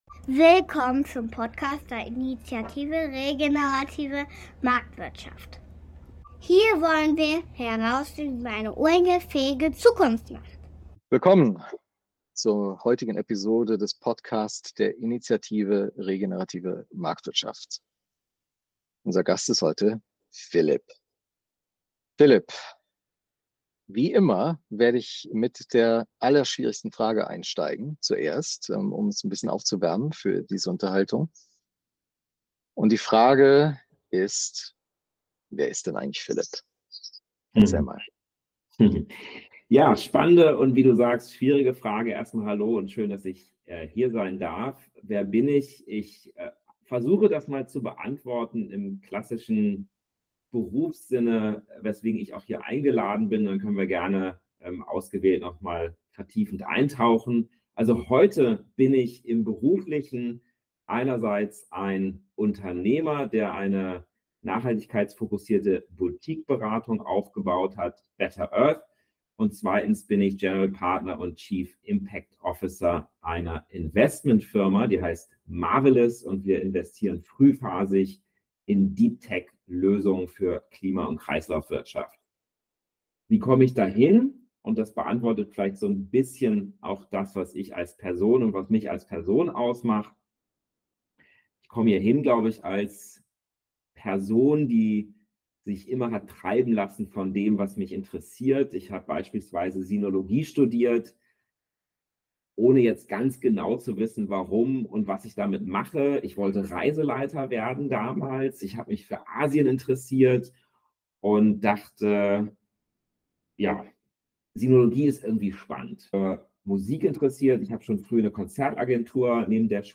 Das Gespräch bietet tiefgehende Einblicke in nachhaltiges Unternehmertum und regt dazu an, die Freude an der positiven Gestaltung der Zukunft zu entdecken.